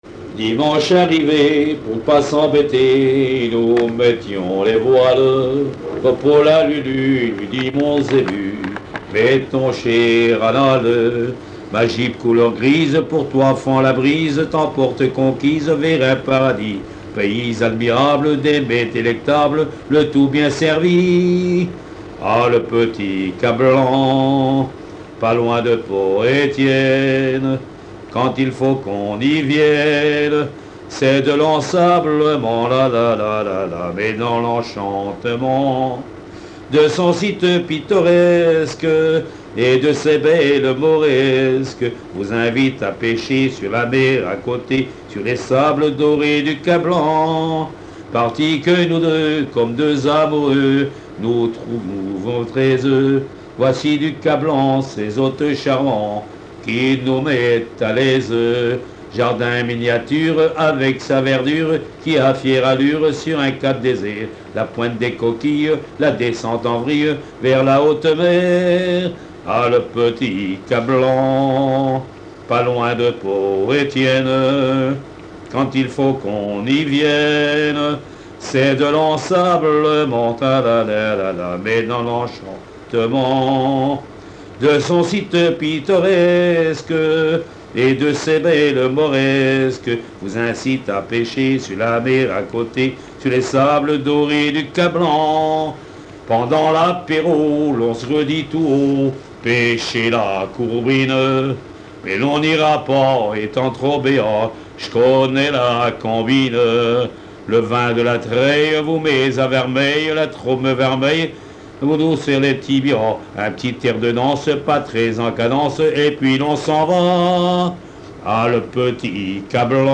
Mémoires et Patrimoines vivants - RaddO est une base de données d'archives iconographiques et sonores.
Chansons populaires
Pièce musicale inédite